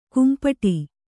♪ kumpaṭi